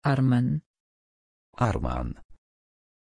Pronunciation of Arman
pronunciation-arman-pl.mp3